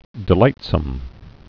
(dĭ-lītsəm)